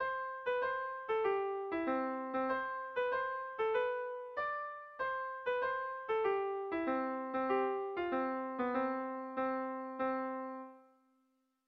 Dantzakoa